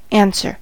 answer: Wikimedia Commons US English Pronunciations
En-us-answer.WAV